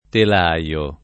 telaio [ tel #L o ]